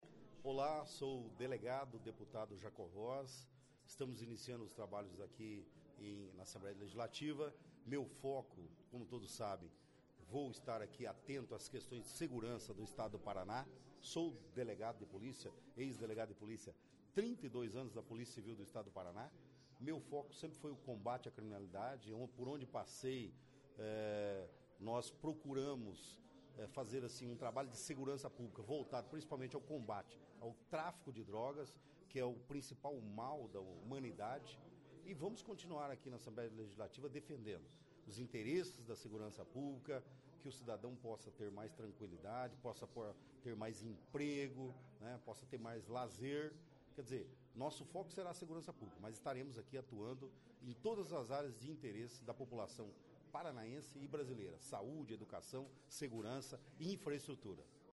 Conheça mais deste parlamentar, que foi delegado de polícia por 32 anos. Ouça a entrevista.